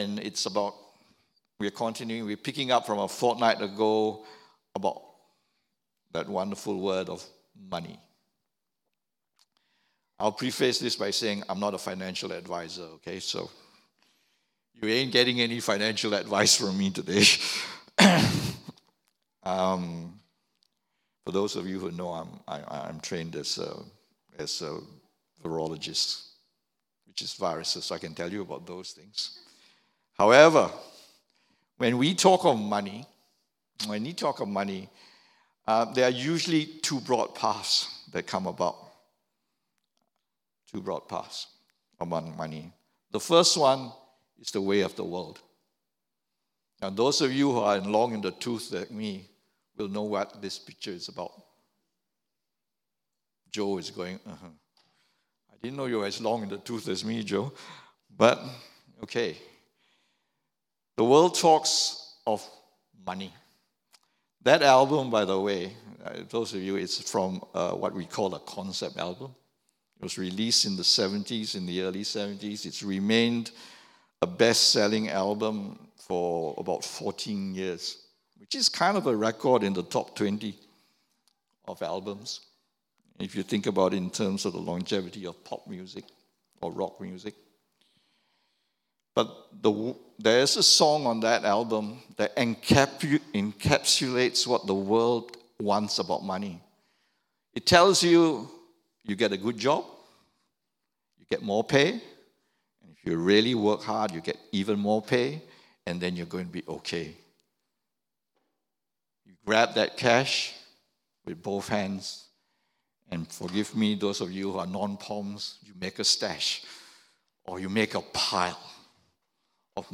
English Worship Service - 18th Jun 2023
Sermon Notes